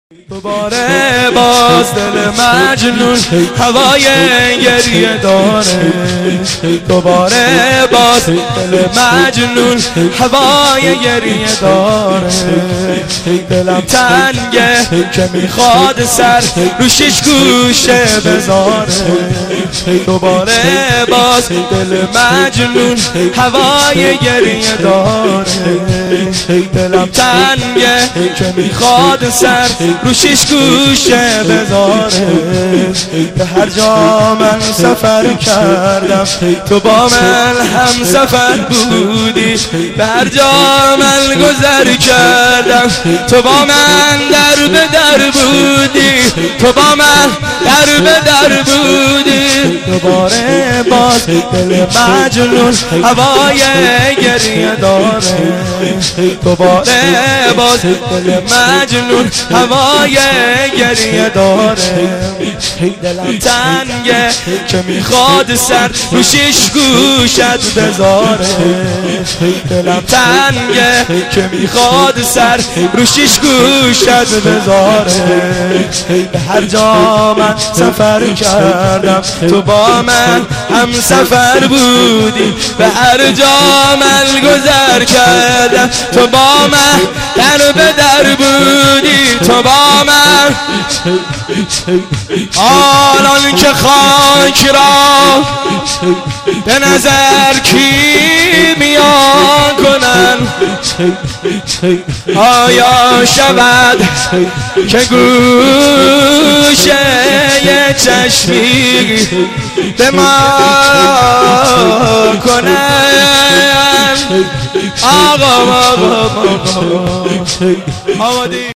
(شور - امام حسین علیه السلام)